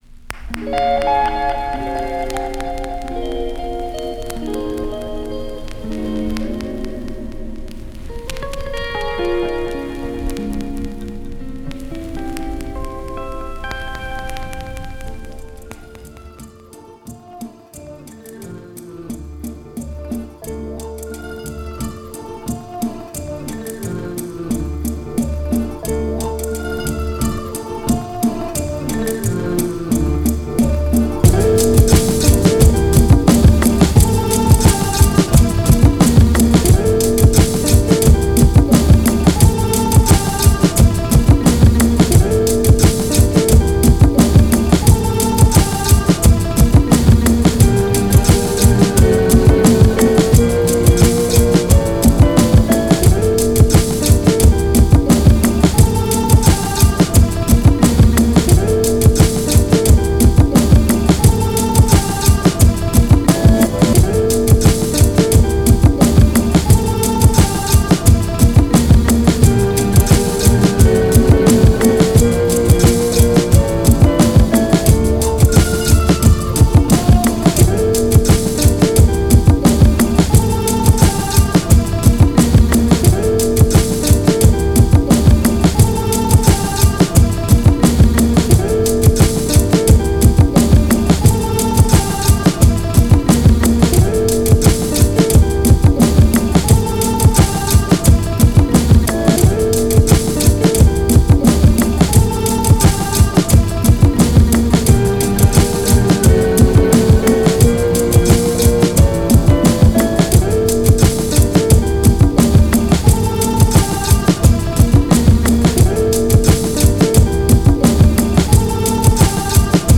found-sound assemblage, w/ a tropical groove
An instrumental of pure invention and relentless groove